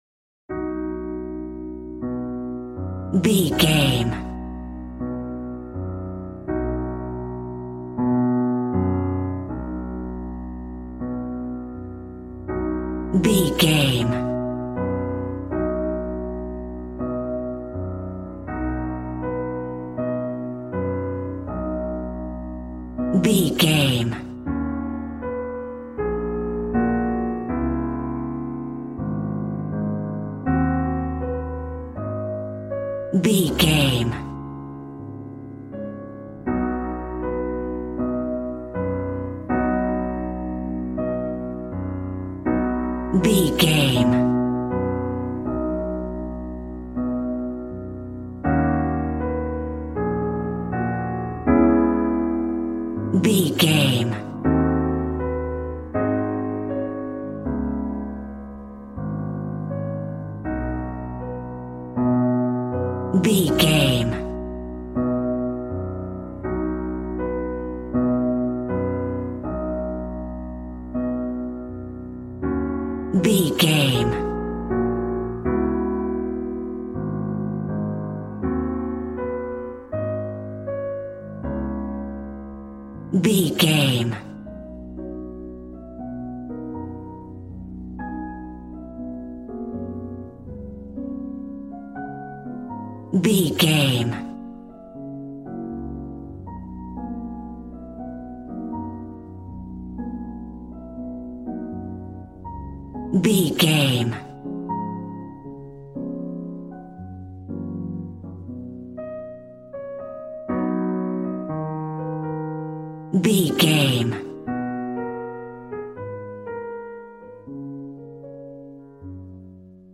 Ionian/Major